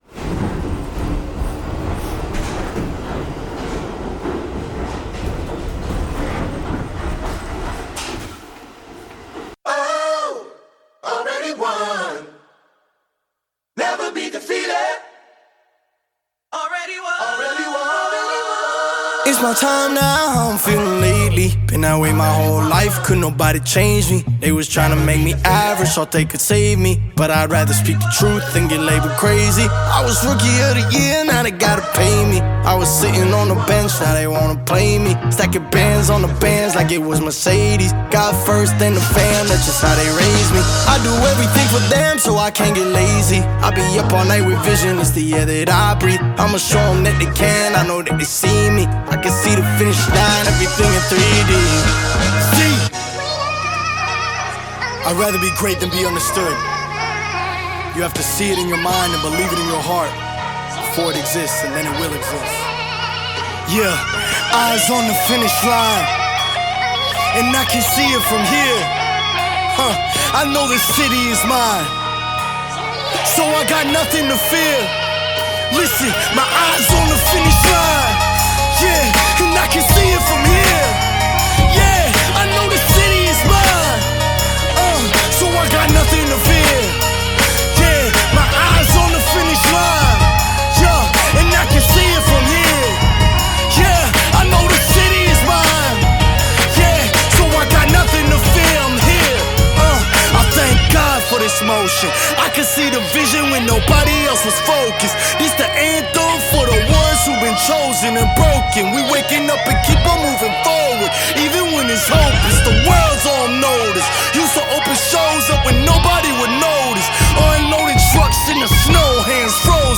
Mp3 Gospel Songs
urban beats
From its infectious rhythm to its poignant lyrics